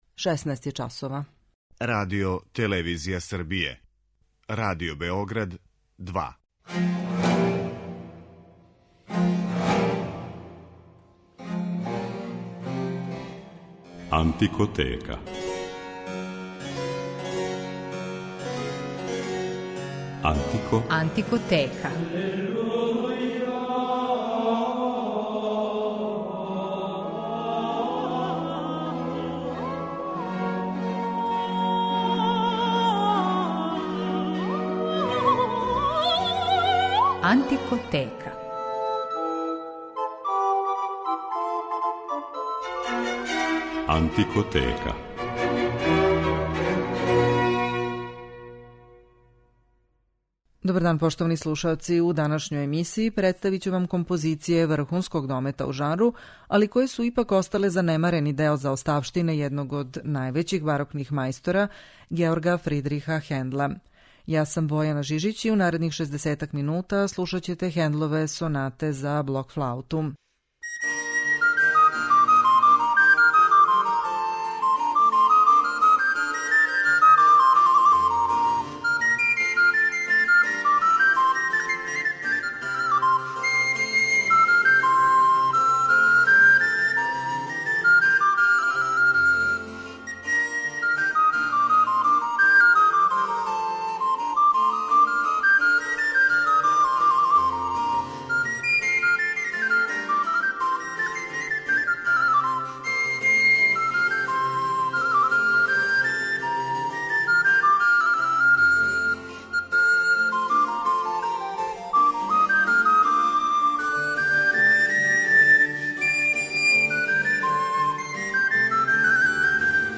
Хендлове сонате за блок флауту